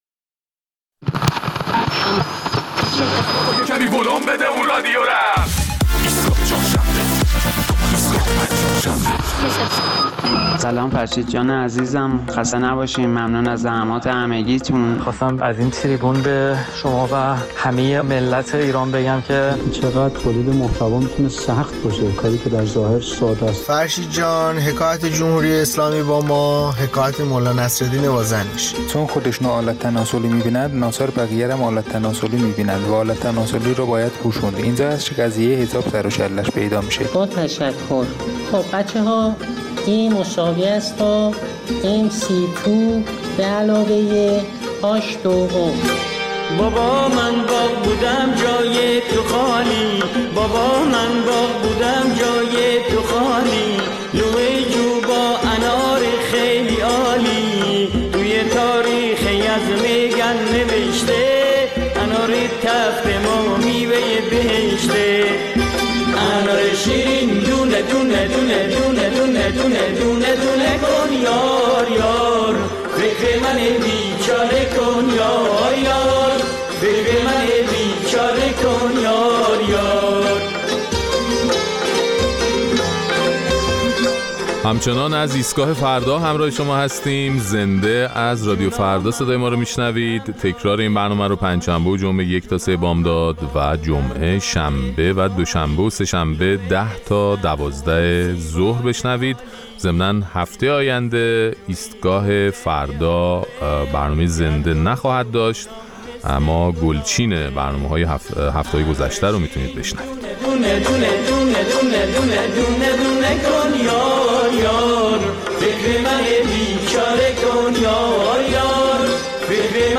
در این برنامه نظرات شنوندگان ایستگاه فردا را در مورد صحبت‌های پرحاشیهء معاون وزیر ورزش و جوانان درباره روسری بر سر چوب زدن دختران و نتایج آن می‌شنویم.